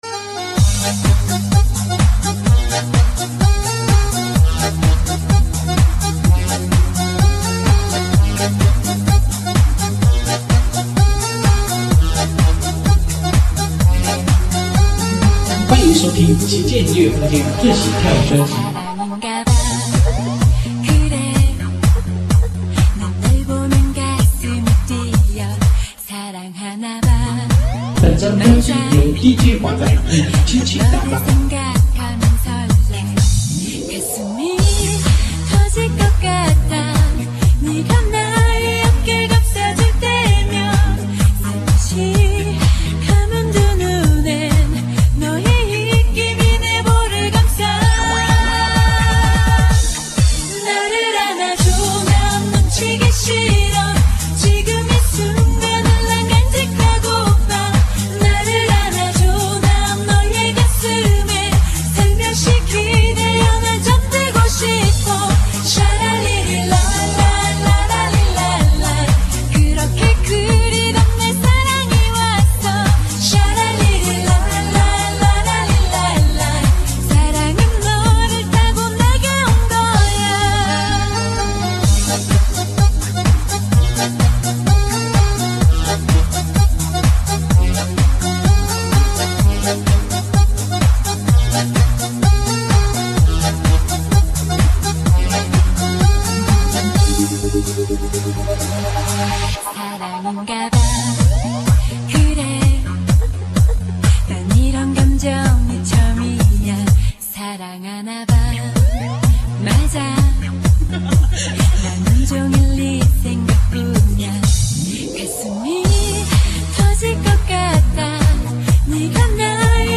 串烧HI曲首首好听